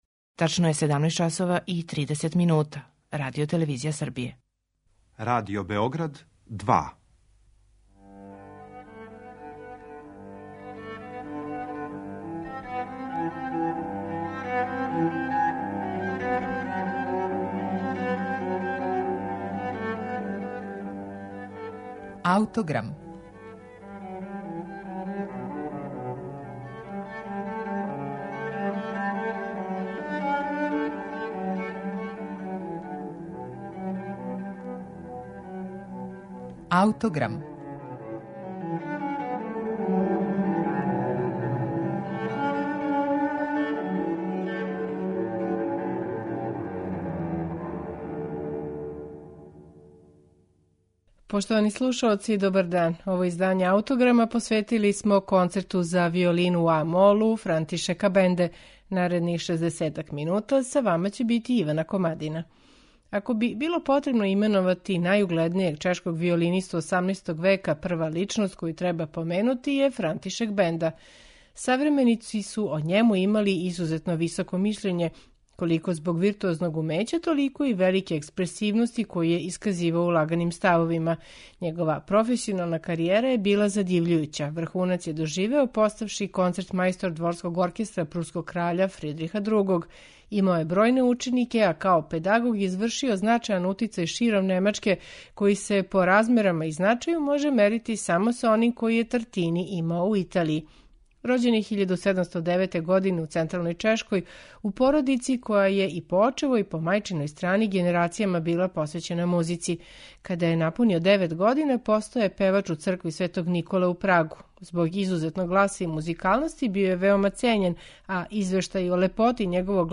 Виолински концерт у а-молу Франтишека Бенде